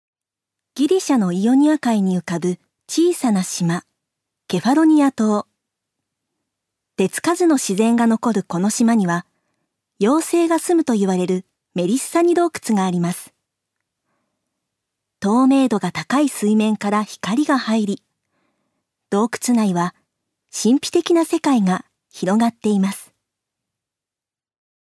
女性タレント
音声サンプル
ナレーション２